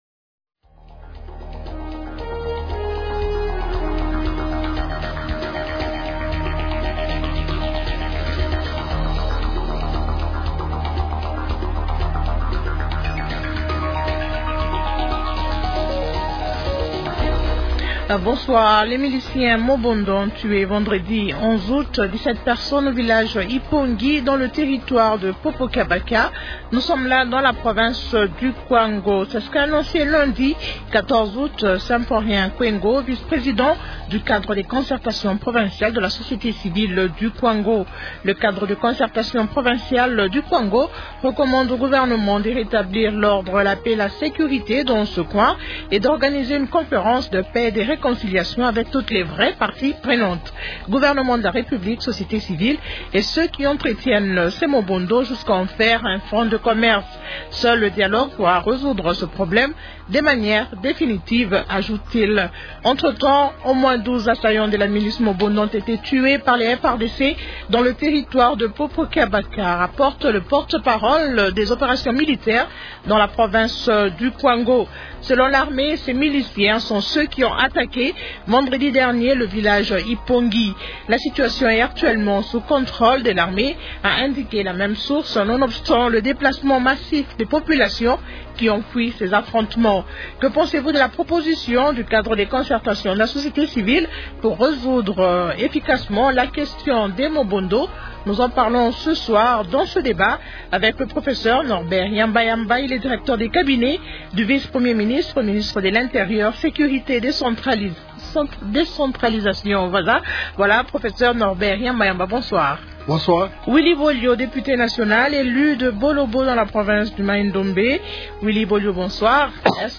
-Willy Bolio, député national élu de Bolobo dans la province du Maindombe.